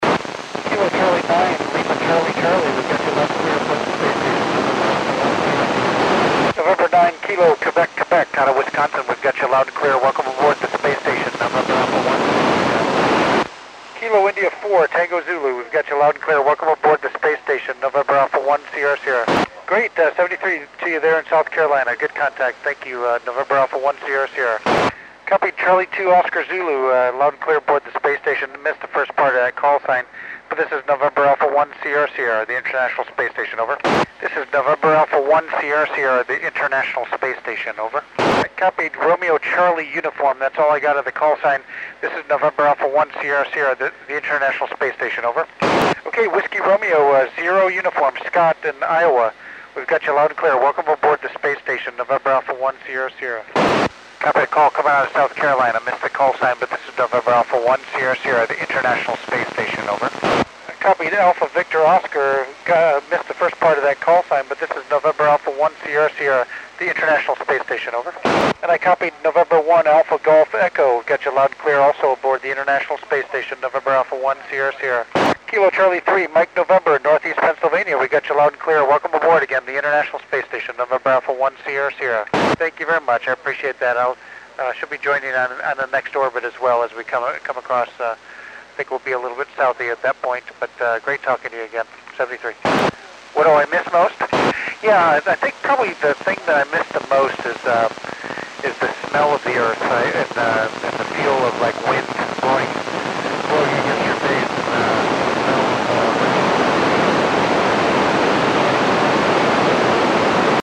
Col. Doug Wheelock works U.S. stations on 17 September 2010 at 2142 UTC.